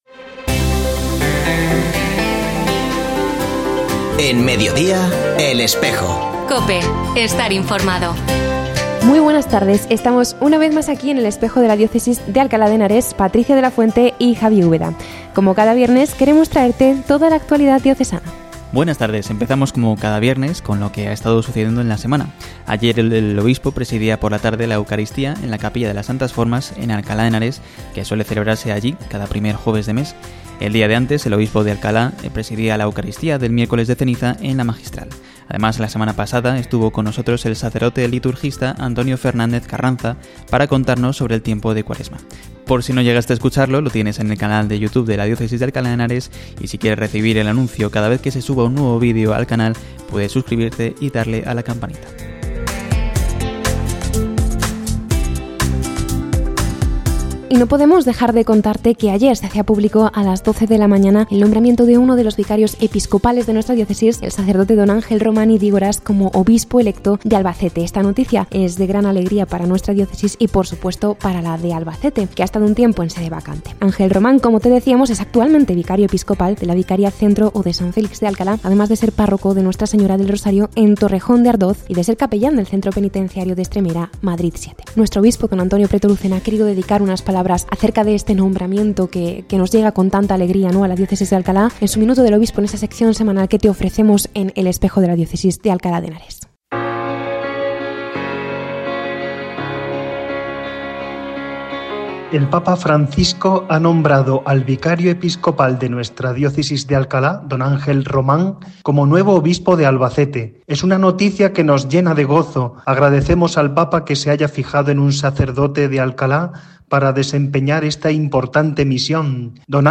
Hoy, 7 de marzo de 2025, fiesta de la Reversión de las Reliquias de los Santos Niños, se ha emitido un nuevo programa de El Espejo de la Diócesis de Alcalá en la emisora de radio COPE.
Por eso, en el programa de hoy escuchamos un extracto de la entrevista que ha realizado la Delegación de Medios de Comunicación Social de la diócesis complutense al que pronto será consagrado obispo.